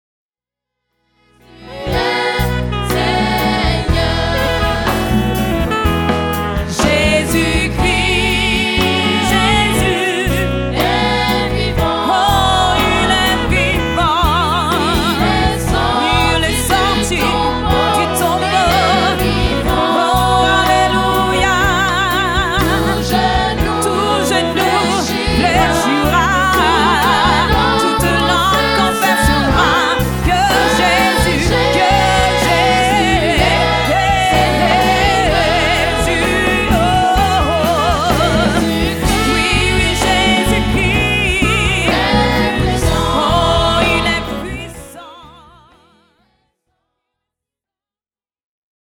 Enregistrement public réalisé à Genève en 2006